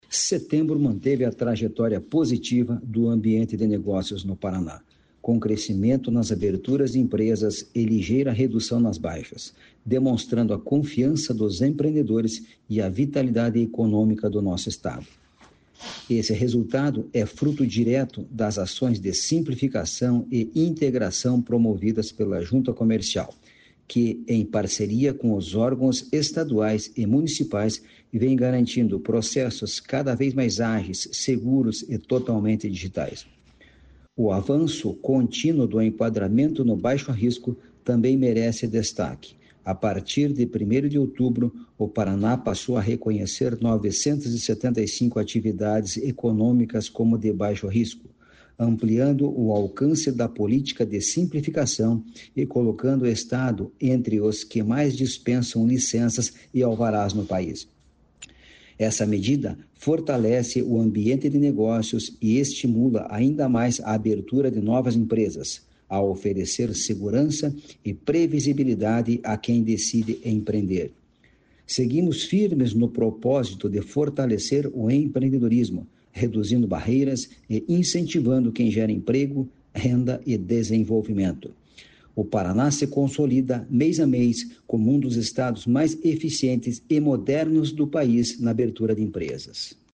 Sonora do presidente da Jucepar, Marcos Rigoni, sobre o crescimento no registro de empresas